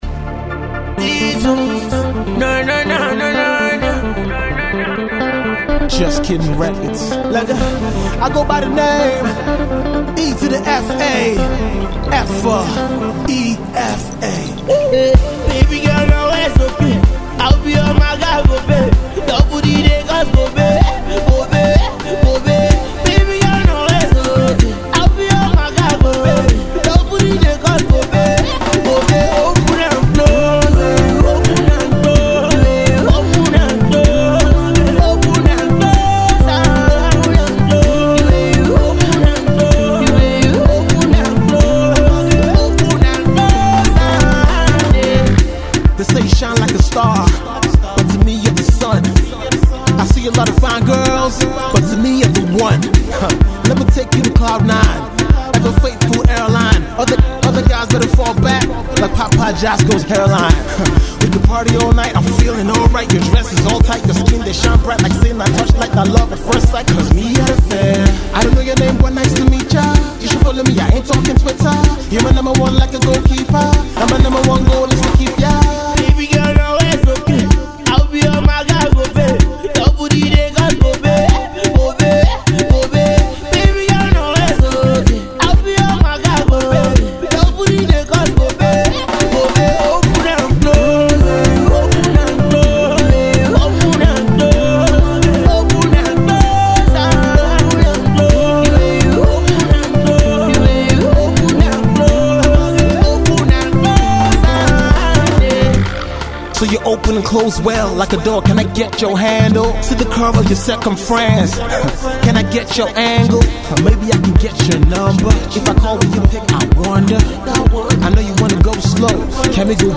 funky
dance songs